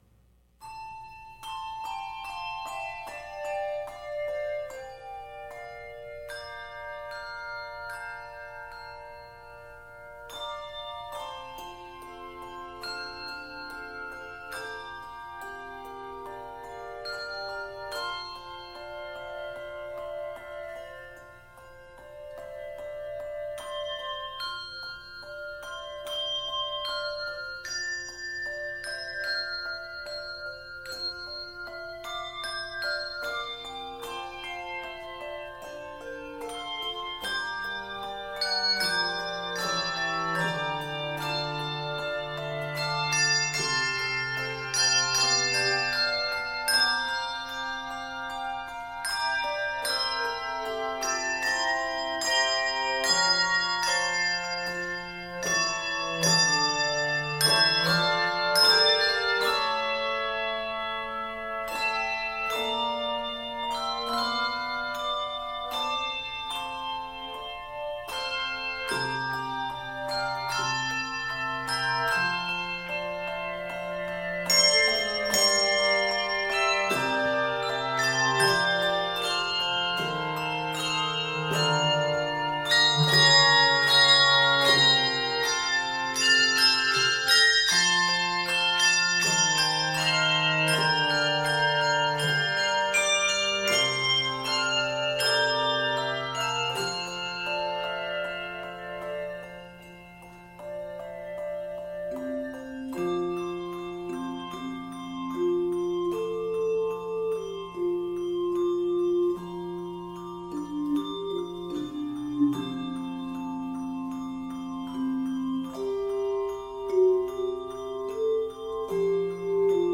meditative arrangement
Key of F Major.
Octaves: 3-5